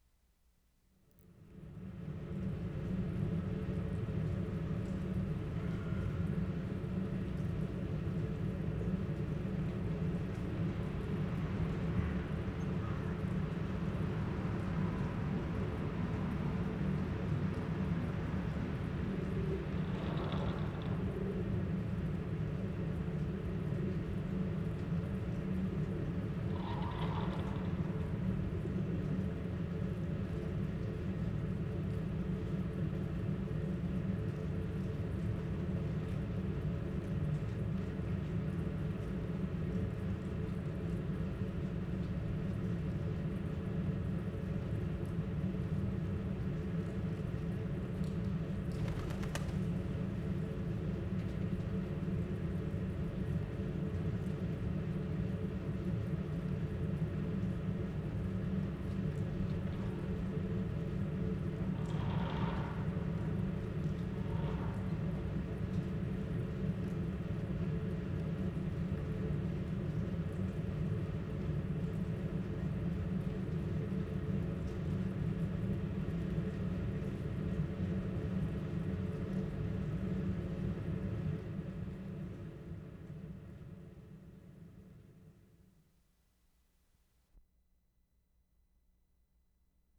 Salzburg, Austria March 16/75
VENTILATOR NOISE, at site of preceding recording. Close up recording (2 min.).
2. This sound was, at about 2.5 metres from the microphones, part of the keynote context of the previous recording.